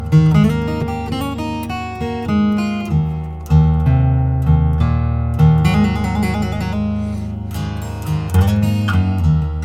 Ist jetzt nicht zum Kennenlernen meiner unermesslich großen Werke, aber zum Vergleich der Mikrofone in Bezug auf die Aufnahme einer Westerngitarre. Habe also 2 Takte angezupfelt und mit den zwei verschiedenen Mikros aufgenommen: 1. Das AT 2035 unbearbeitet (Großmembran Elektret-Kondesator) 2. Der Billigheimer von Conrad, ein Mc Cript EM 280 (Kleinmembran Elektret-Kondensator) Bei beiden wurde das Signal im nachhinein verstärkt. 3. ist die Aufnahme 1 mit dem AT 2035, aber mit dem Equilizer bearbeitet, bei Orientierung an das SM 57.